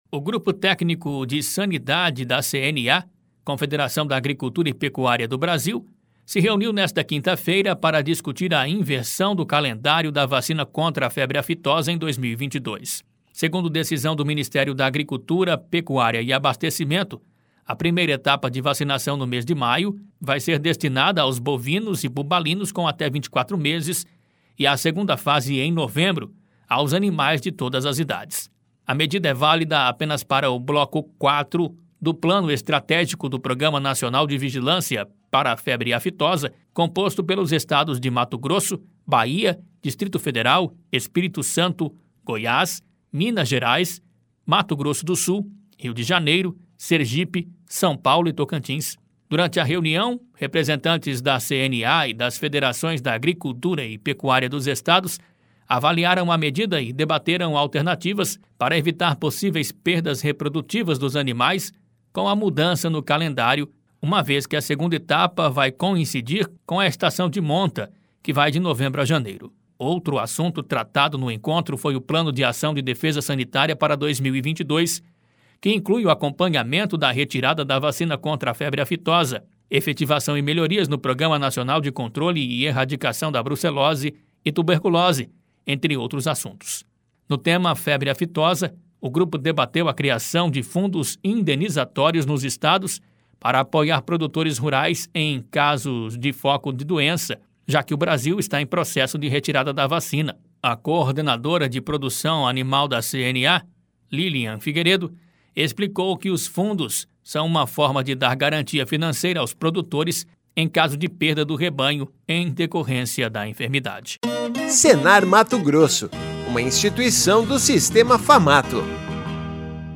Sapicuá Comunicação – Assessoria e Produções em Áudio Radioagência Sápicuá de Notícias